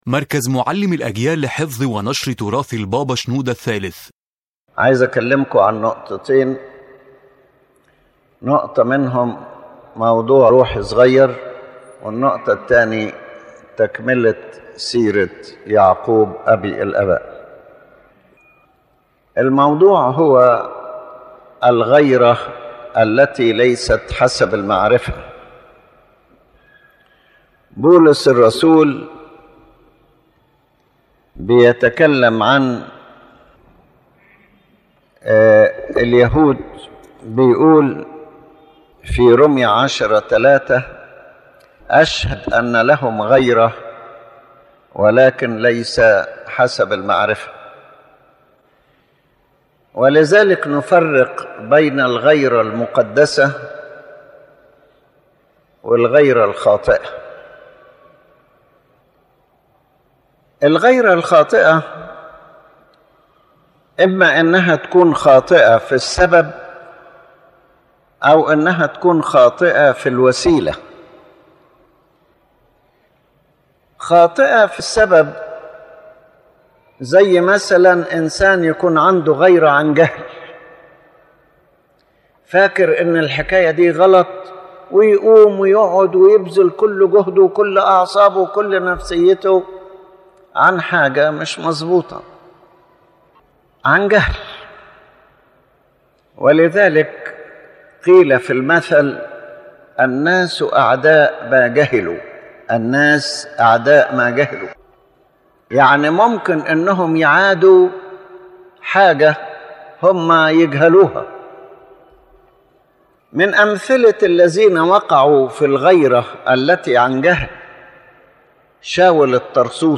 Introduction and general content His Holiness Pope Shenouda III defines incorrect jealousy and explains the difference between it and holy jealousy. He explains that some people feel a “religious” jealousy but its motives or means are wrong, or that it results from ignorance.